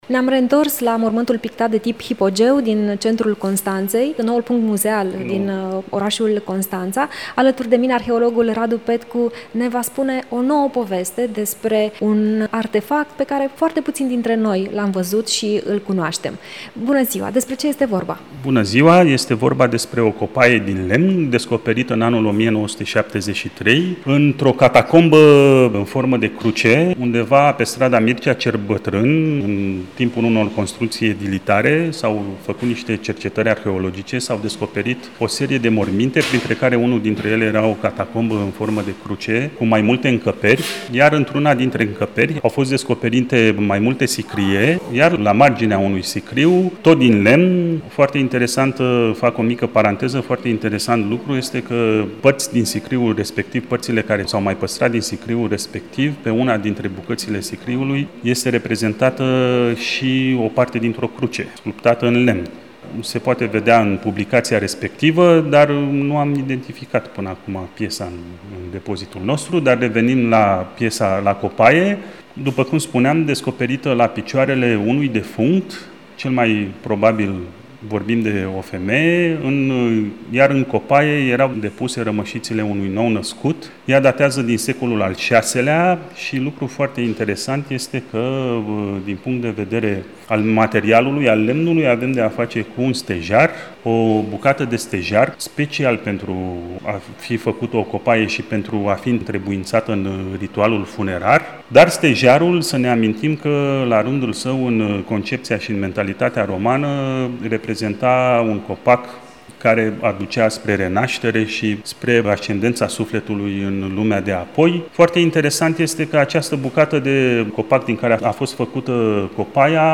într-un dialog